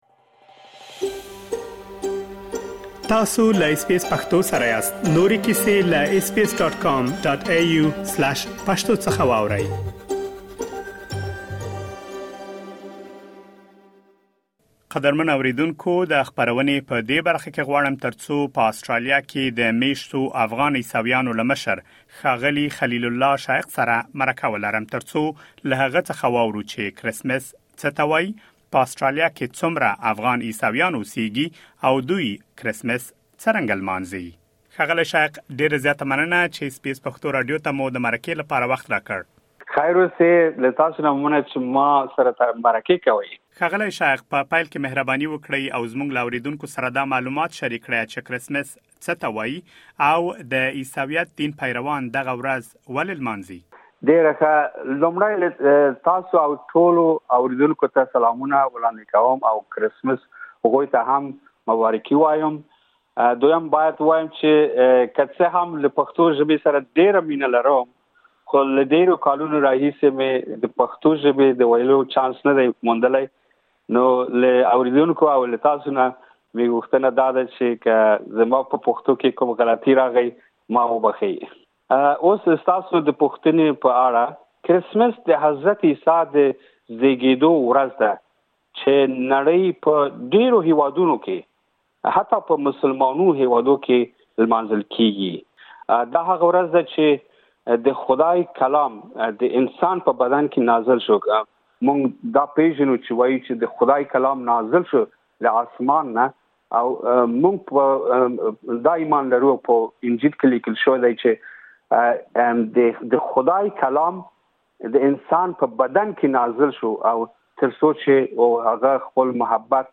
اس بي اس پښتو په آسټرالیا کې د مېشتو افغان عیسویانو له یوه مشر سره مرکه ترسه کړې.